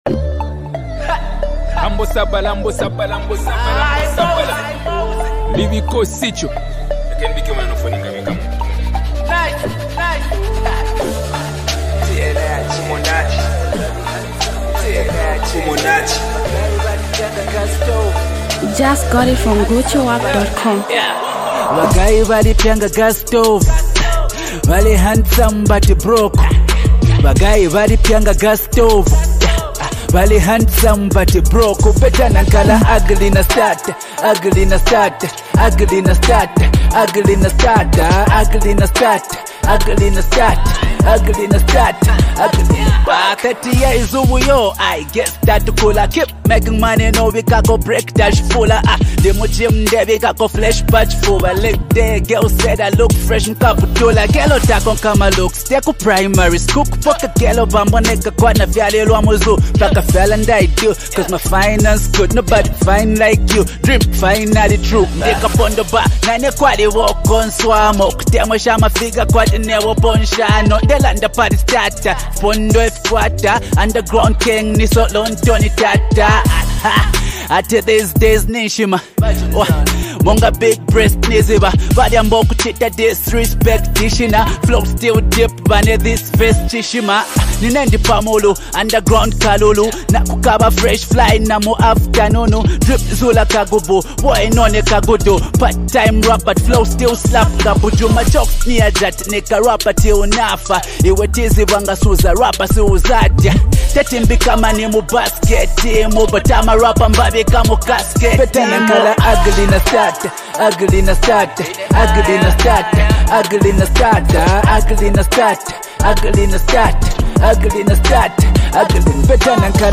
A skilled rapper who brings his unique flow